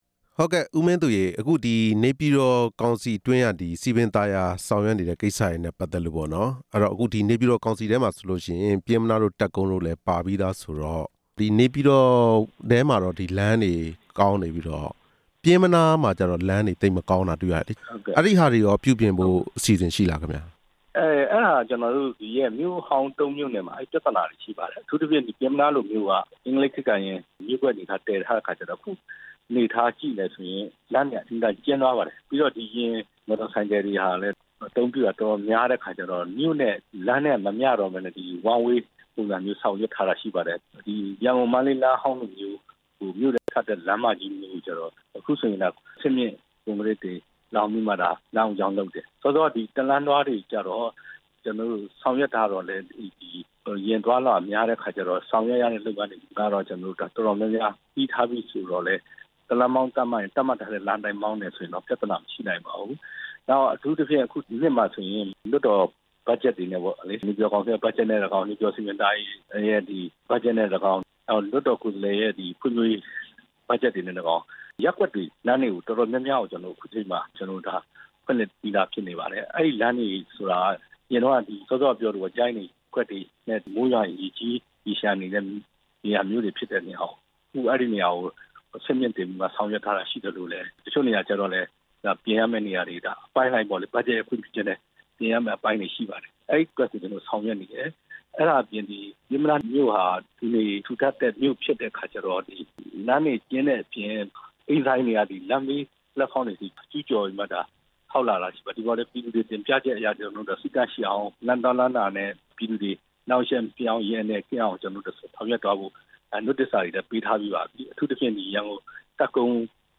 နေပြည်တော်စည်ပင်သာယာရေး ကော်မတီဝင် ဦးမင်းသူနဲ့ မေးမြန်းချက်